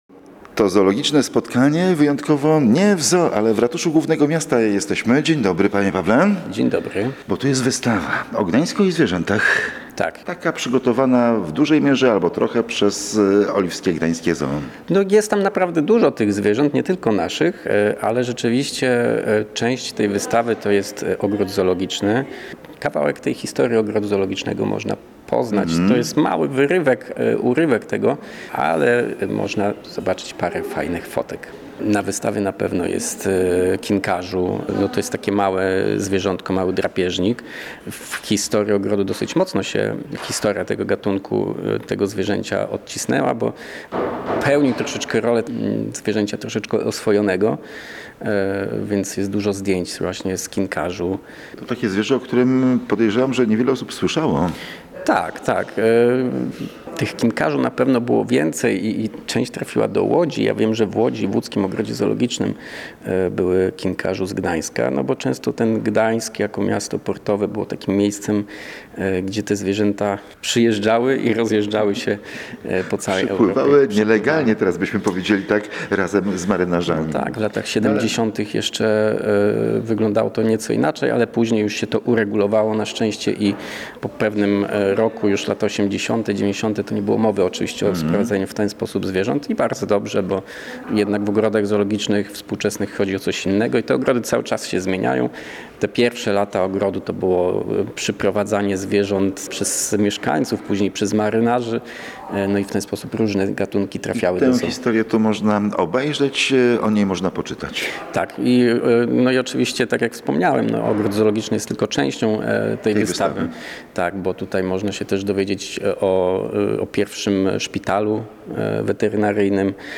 Kolejny odcinek audycji "Zoo opowieści" jest nieoczywistym spotkaniem ze zwierzętami z oliwskiego zoo. Nie w ogrodzie zoologicznym, ale w Ratuszu